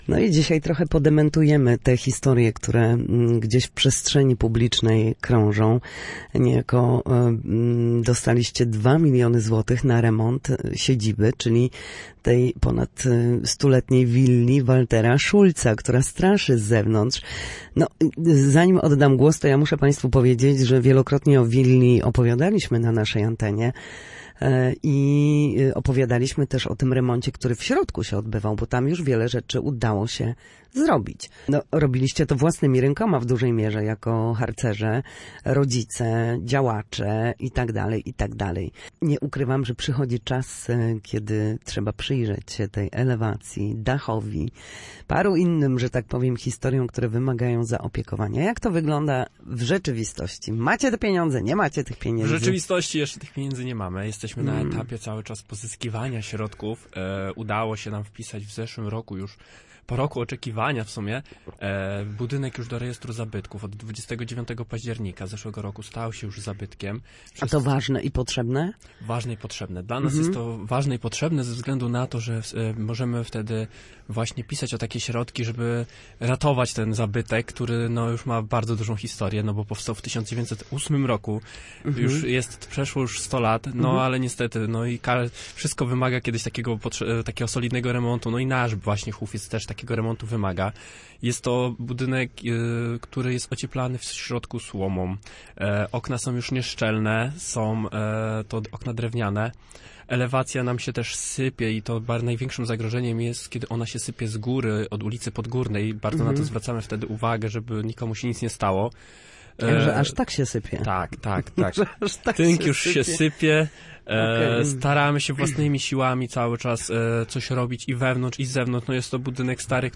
był gościem Studia Słupsk. Na nasze antenie mówił o potrzebie remontu siedziby słupskiego hufca.